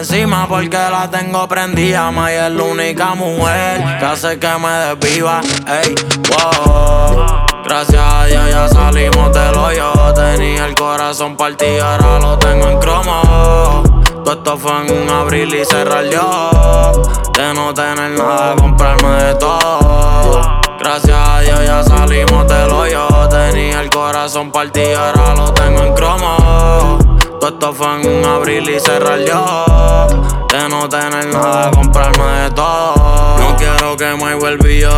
Latin Urbano latino
Жанр: Латино